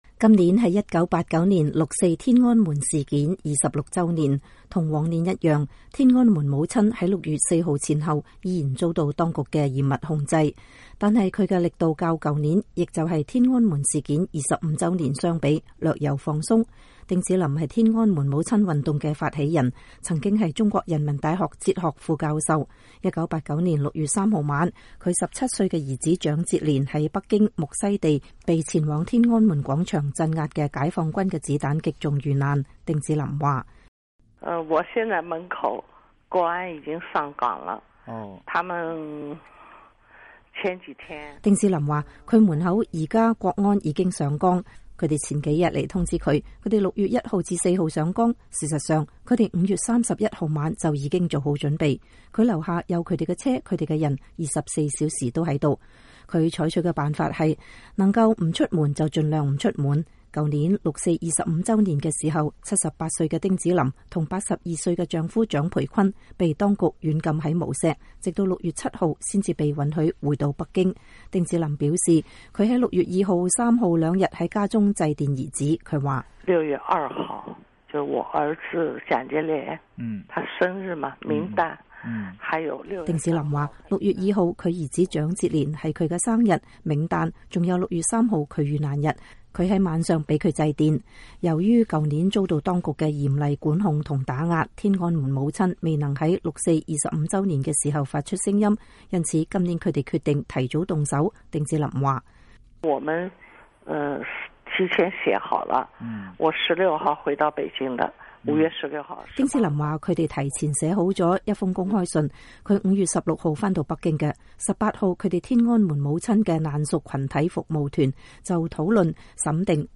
專訪丁子霖: 中共不能迴避六四屠殺